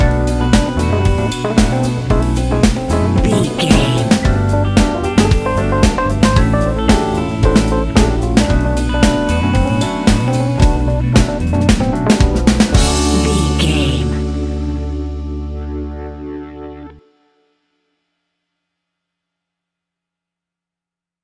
Ionian/Major
D♭
house
electro dance
synths
techno
trance